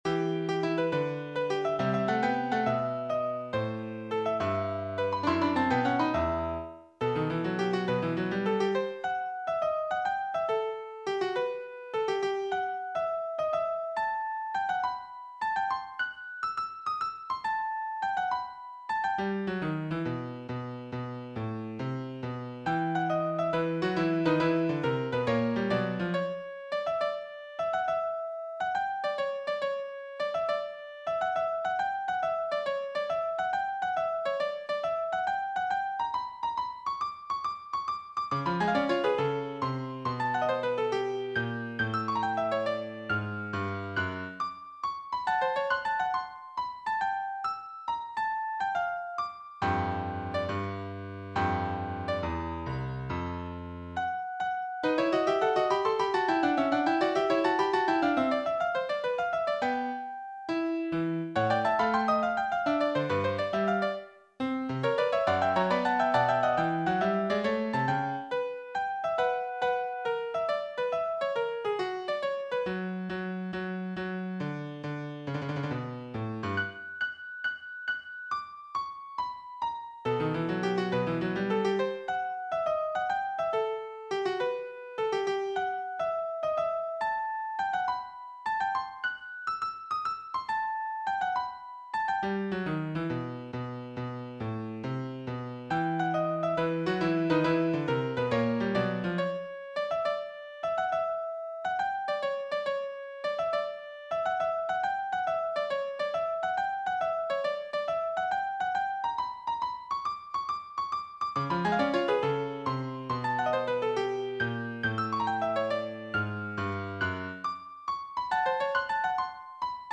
Sketches for Beethoven’s Piano Sonata in E, Opus 109, Second Movement
A falling circle of fifths sequence then takes the idea to the end of page 39.
Upon this second ending, he returns to a statement of the theme in A minor in the bass (noted on page 38, stave 6-8)